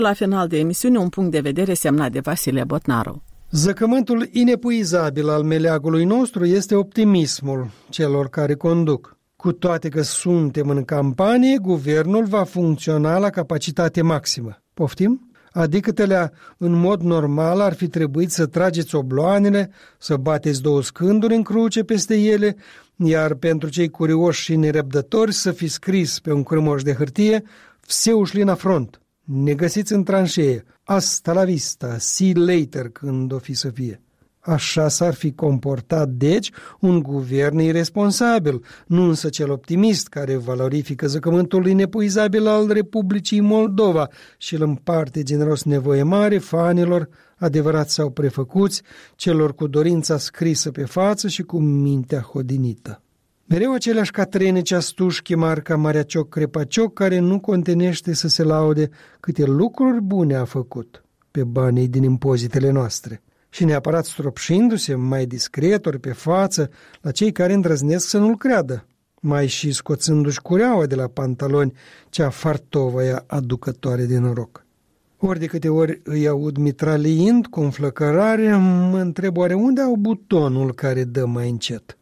în studioul Europei Libere la Chișinău